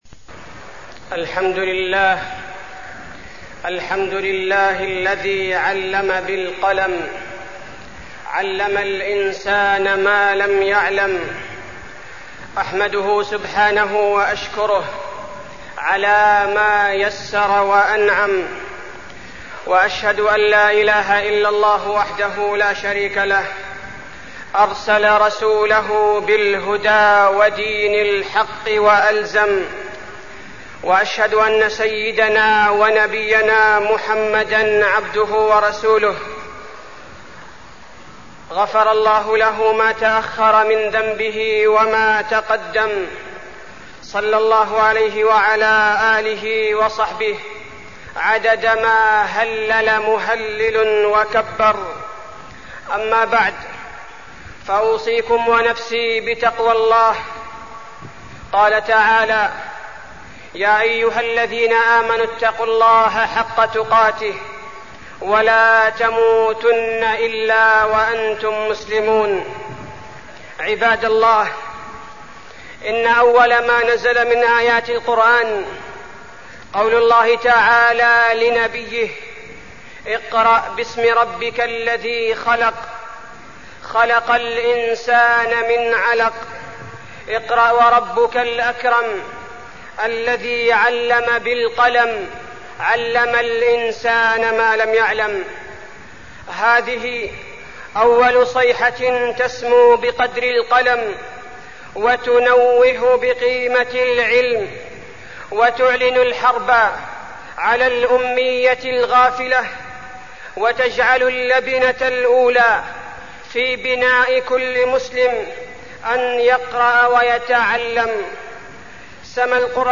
خطبة العلم والتعلم وفيها: اللبنة الأولى للمسلم أن يقرأ ويتعلم، وحث النبي على التزود من العلم، وأهمية الأدب في التعلم، وأهمية إخلاص المعلم في التعليم
تاريخ النشر ٢٣ شوال ١٤٢٣ المكان: المسجد النبوي الشيخ: فضيلة الشيخ عبدالباري الثبيتي فضيلة الشيخ عبدالباري الثبيتي العلم والتعلم